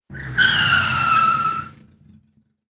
Car travel Accident
accident car car-accident cars clang cling crash distruction sound effect free sound royalty free Memes